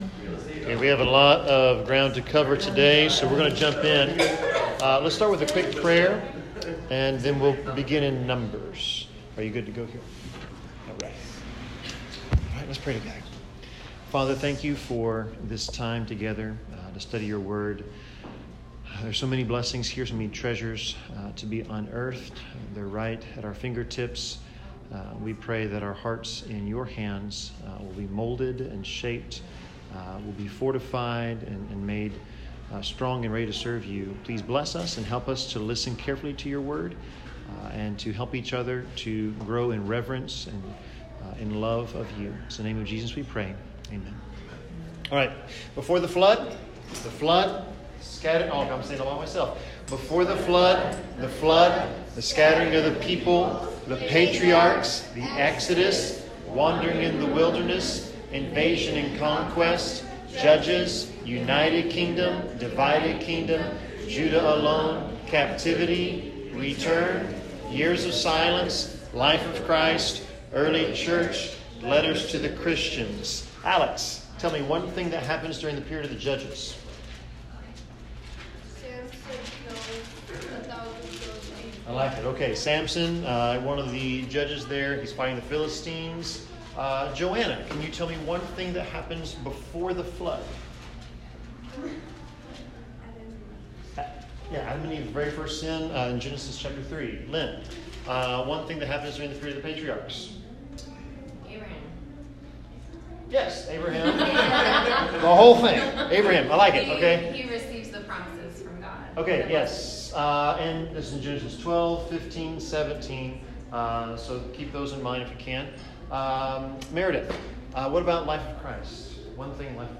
Bible class 11/27/2022